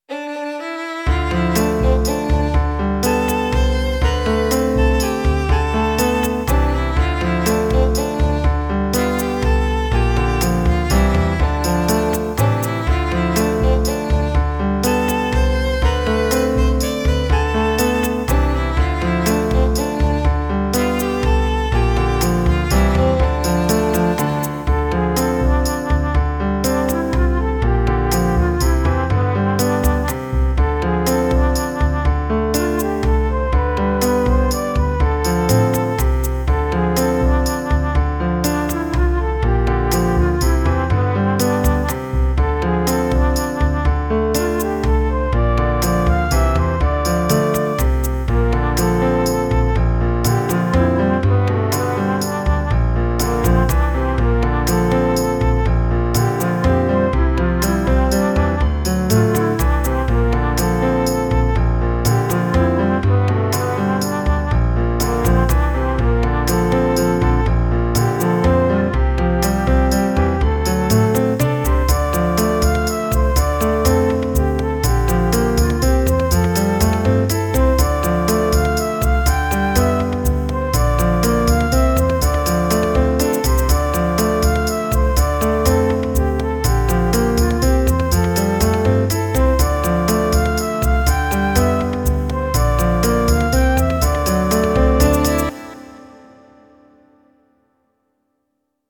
メロディラインとピアノ伴奏だけだったものに、リズム隊とベースを足していきます。
リズムは、異世界若しくはケルト系で旅楽団の演奏という設定ですので、ドラムではなくパーカッションを適当に組み合わせることにしました。
また、ベースはエレキベースやシンセベース等は使えないので、ウッドベースにします。
とりあえず一番は伴奏少な目、二番はしっかりと伴奏を入れて盛り上がりをつけていきたいと考えています。
少し時間を置いて聴いてみると、少しサビのパーカッションが多いように感じました。